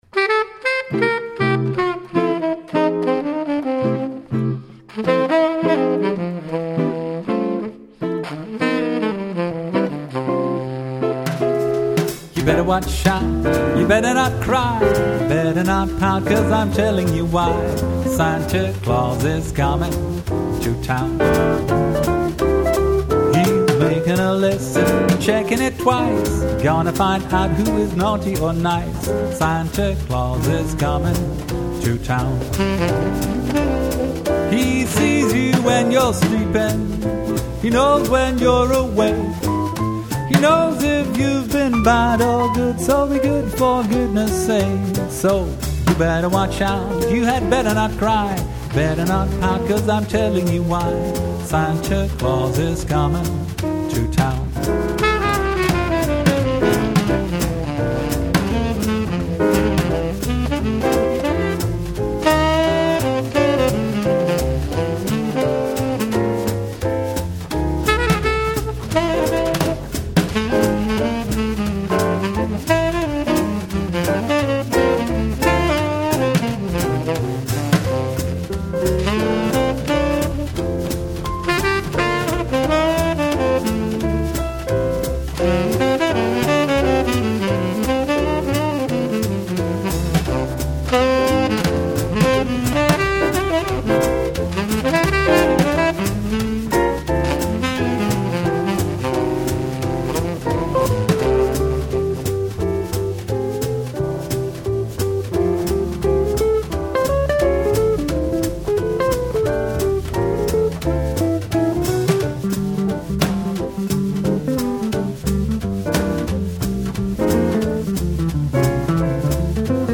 Jazz
Zang
Tenorsax
Gitaar
Piano
Drums